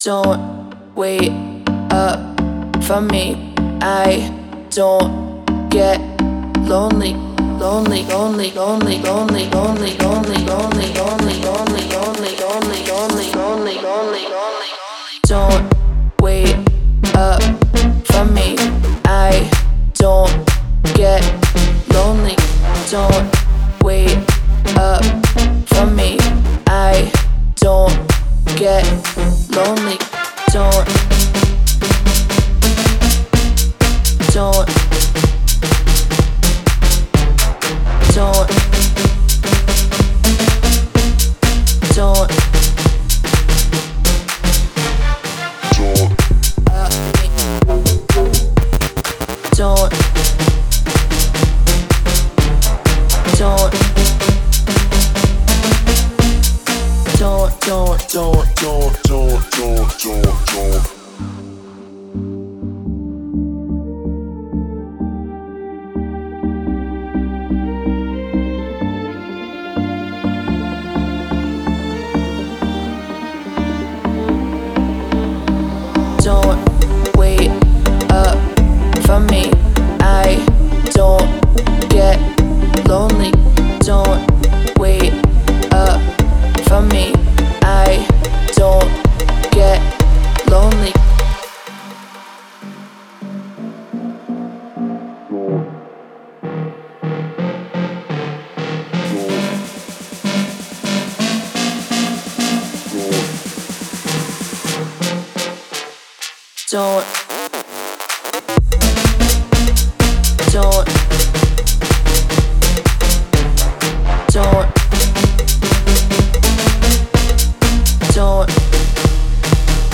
это эмоциональная песня в жанре поп